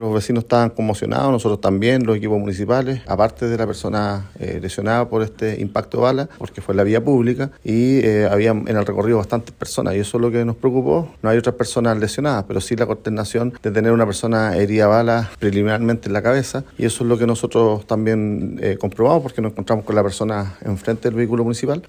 Así lo relató a los micrófonos de La Radio.